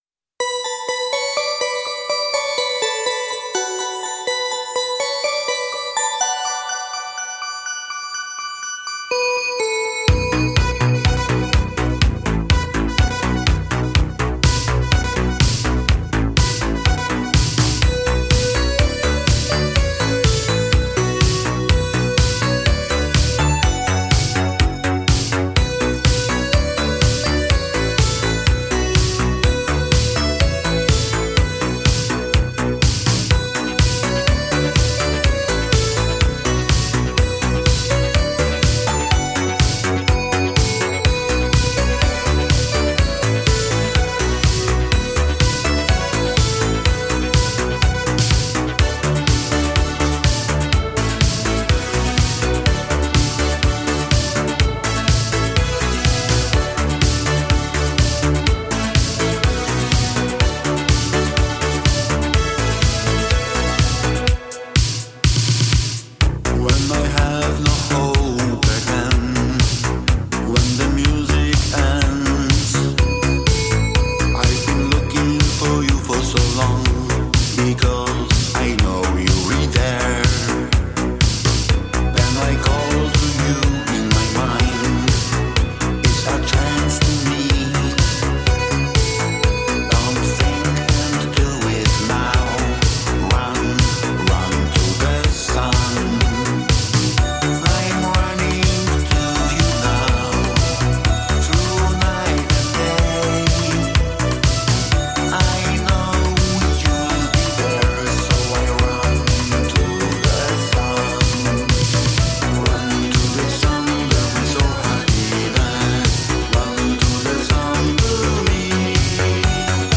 dj舞曲
音乐风格: Italo-Disco / Euro-Disco